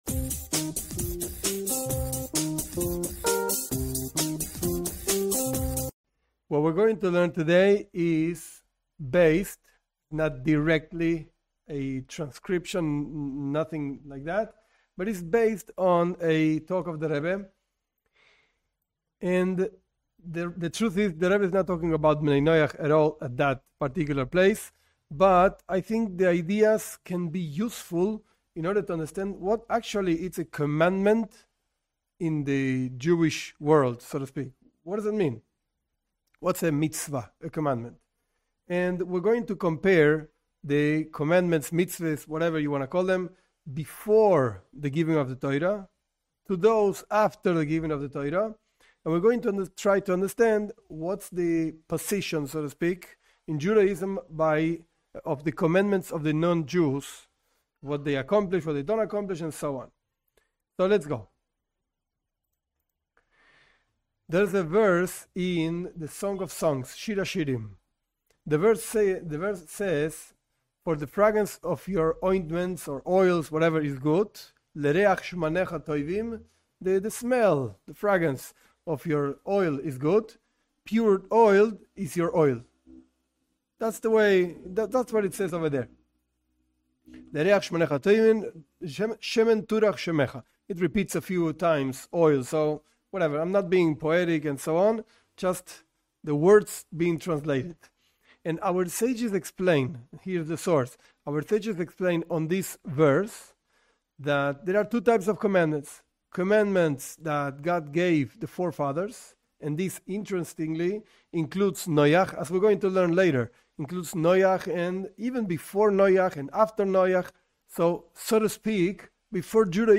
This class explains the difference in the commandments from before to after the Giving of the Torah, and explains its implications to non Jews, Bnei Noach.